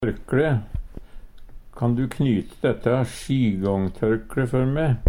tørkLæ - Numedalsmål (en-US)